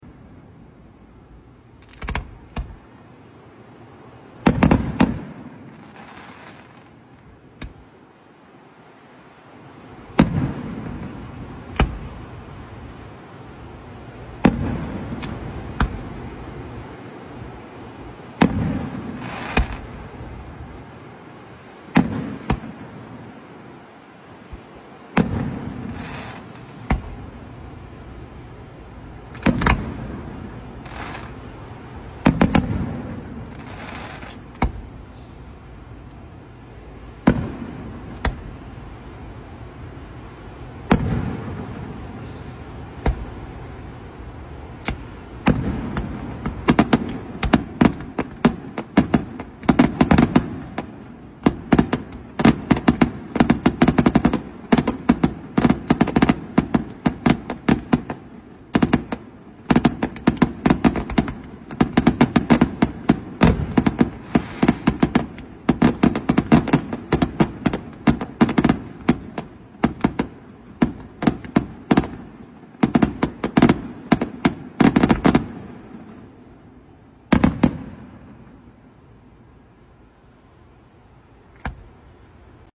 Fireworks Over the ocean sound effects free download
Fireworks - Over the ocean - labor day - Sea Breeze, Long Branch, New Jersey